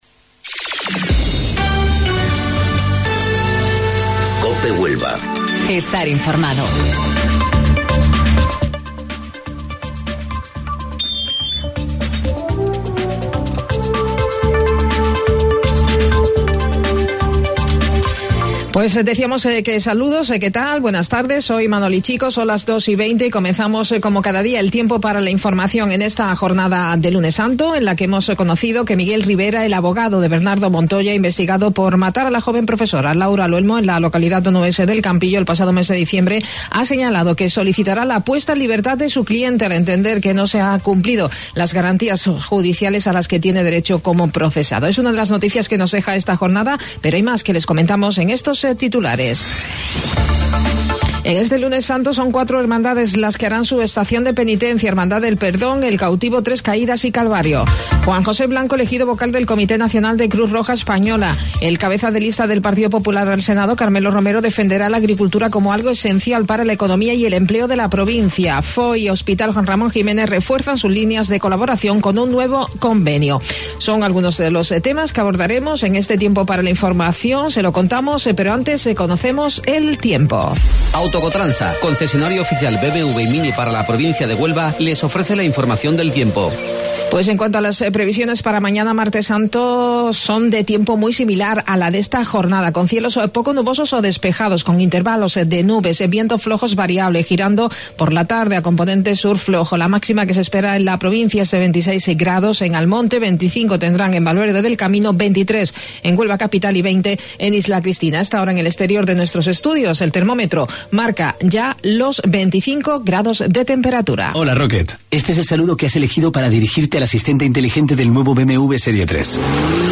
AUDIO: Informativo Local 14:20 del 15 de Abril